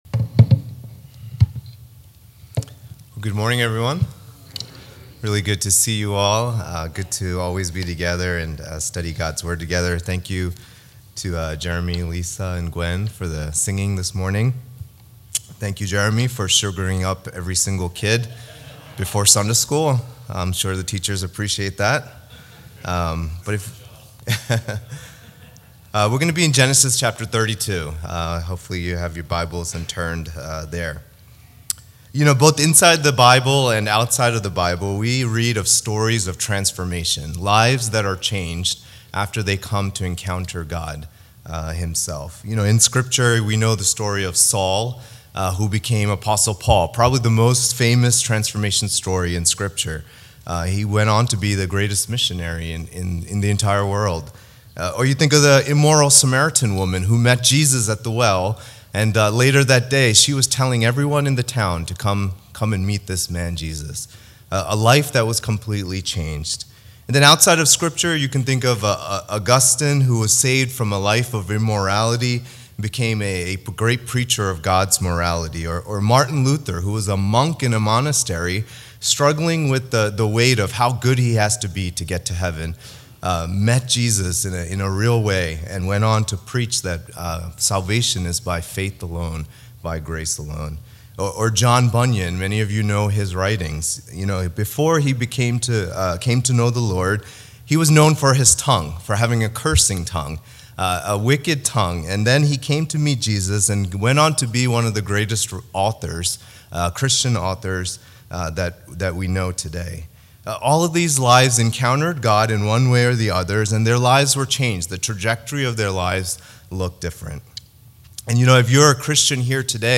All Sermons Genesis 32:1-21